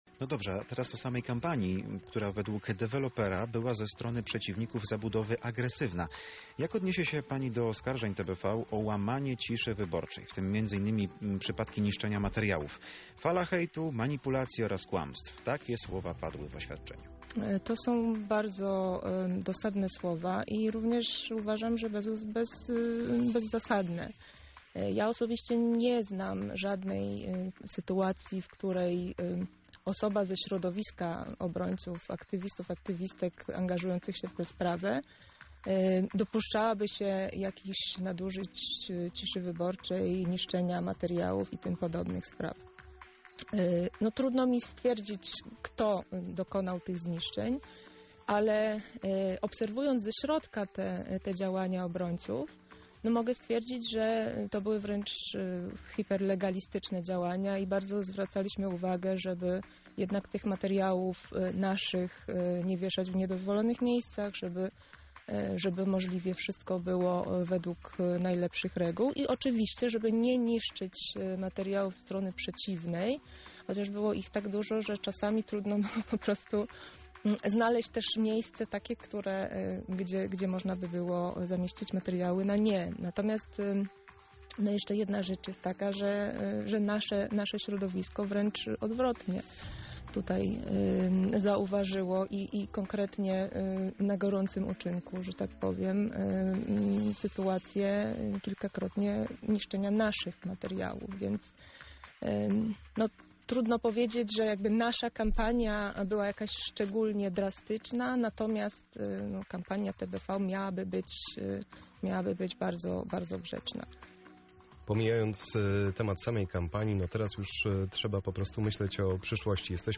W rozmowie poruszyliśmy kwestie kampanii referendalnej, frekwencji oraz przyszłości tego obszaru.